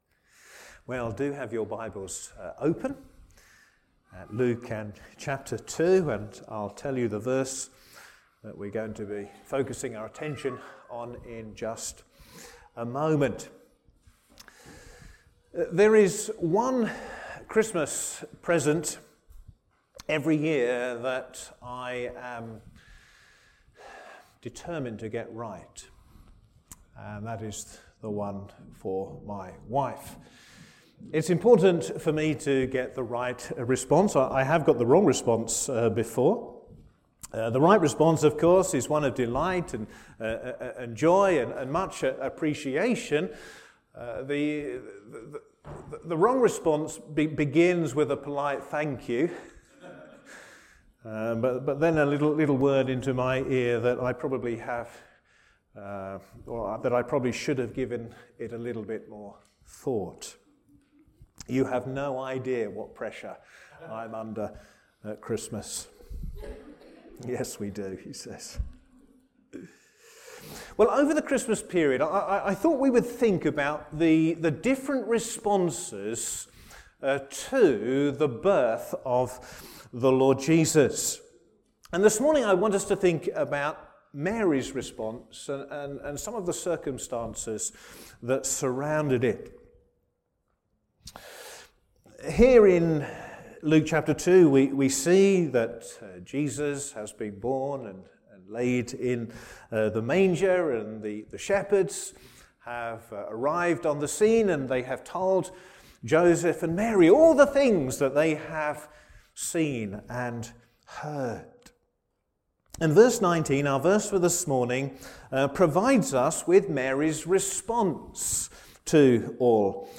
Sermons
Service Morning